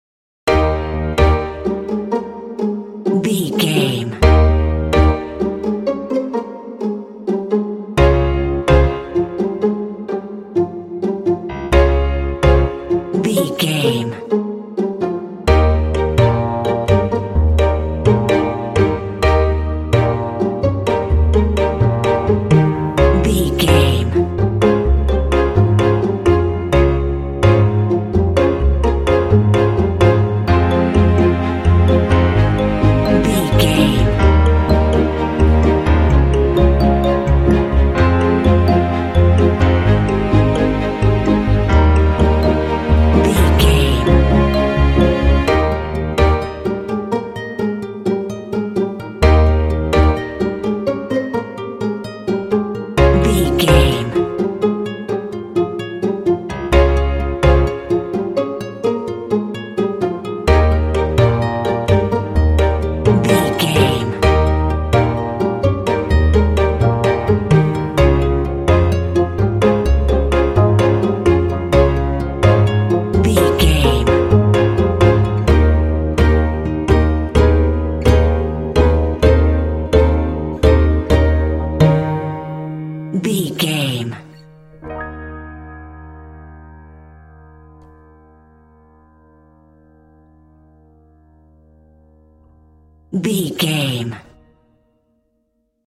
Ionian/Major
E♭
light
happy
uplifting
dreamy
relaxed
strings
double bass
acoustic guitar
piano
contemporary underscore